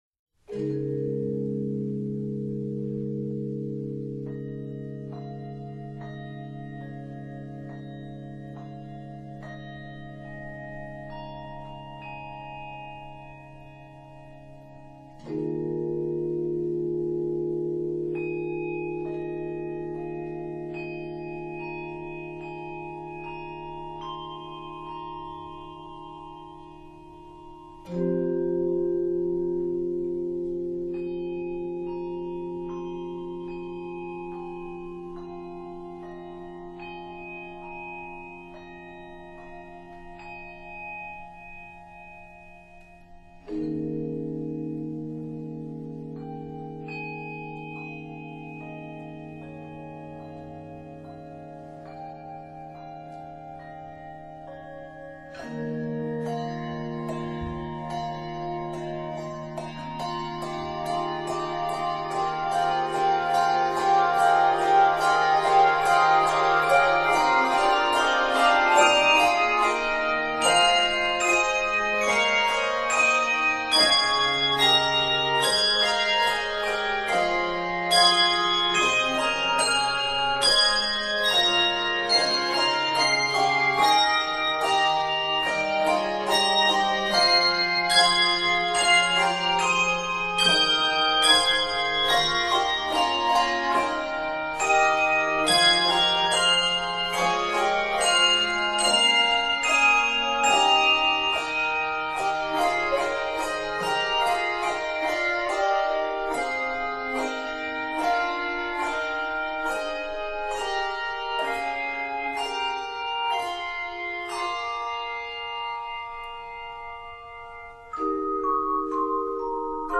A mystical mood is set from the beginning
Octaves: 3 or 5